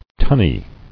[tun·ny]